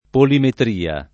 polimetria [ polimetr & a ] s. f. (metr.)